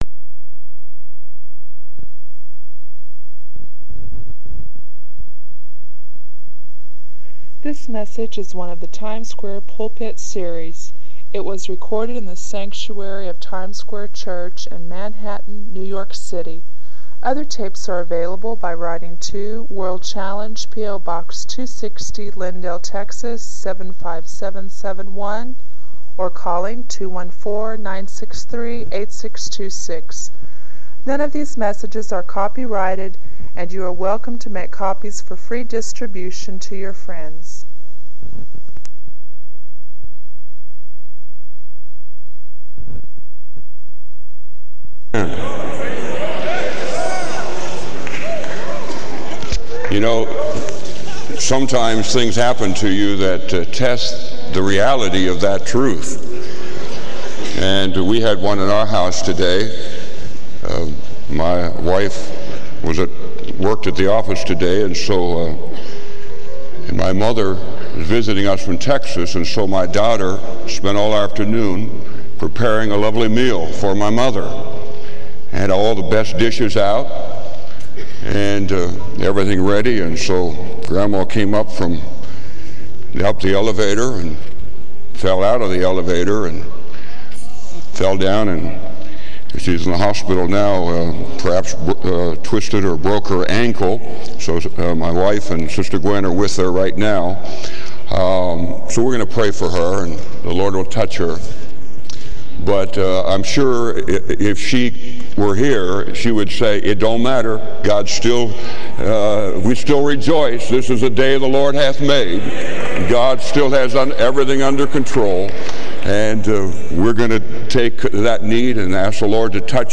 This sermon encourages believers to confront their fears and doubts by anchoring themselves in God's Word and faithfulness.